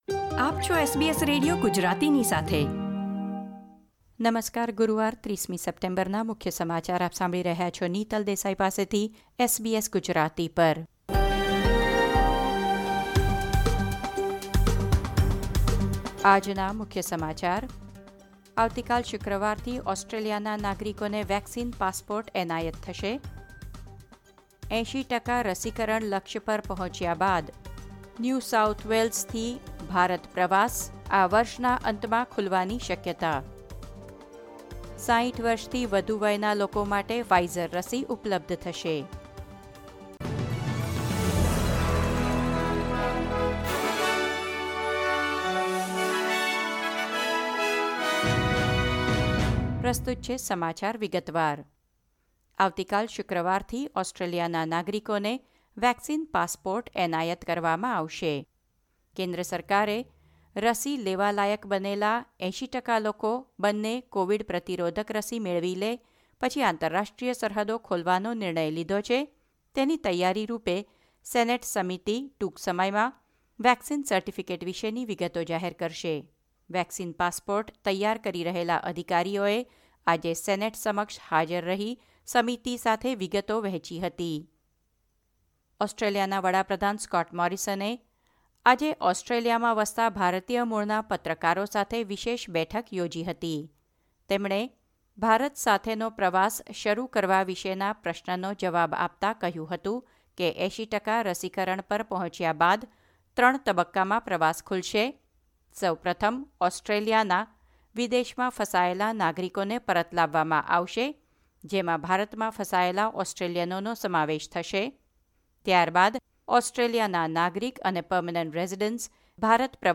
SBS Gujarati News Bulletin 30 September 2021